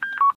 Test beep boops